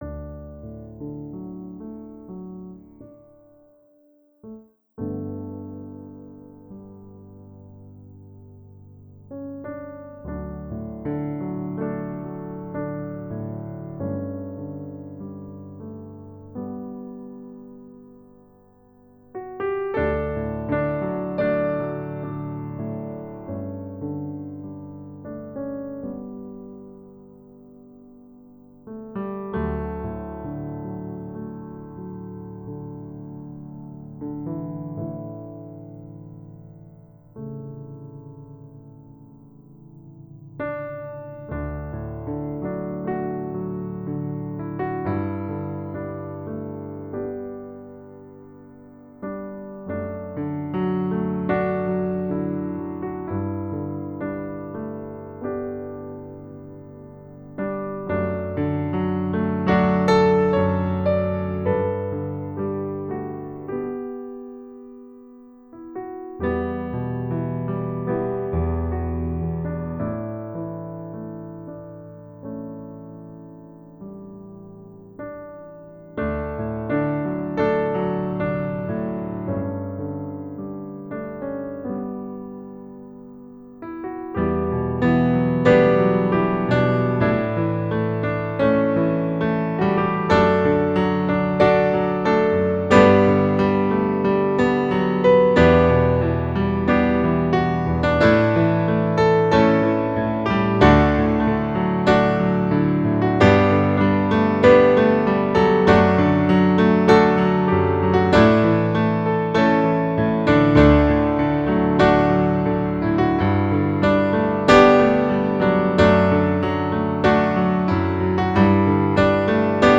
Piano Solo
Voicing/Instrumentation: Piano Solo We also have other 75 arrangements of " I Know That My Redeemer Lives ".